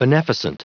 Prononciation du mot beneficent en anglais (fichier audio)
Prononciation du mot : beneficent